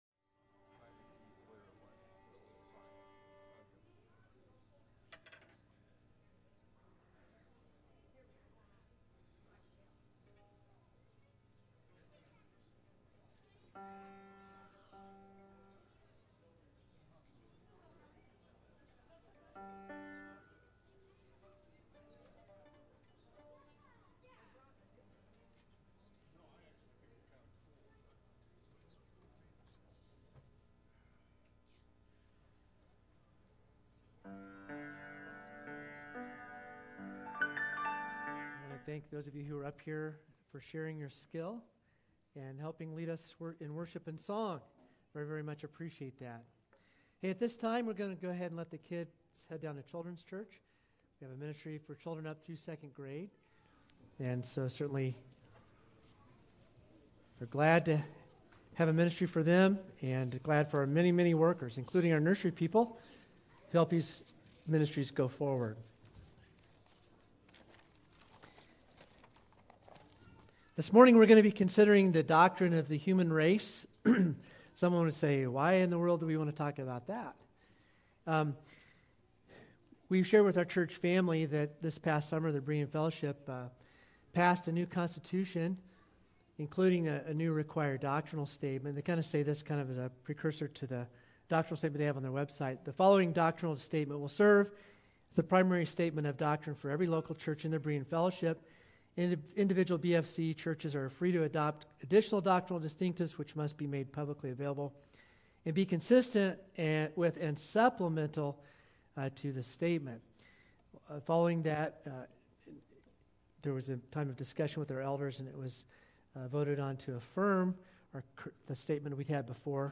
1-30-22-sermon.mp3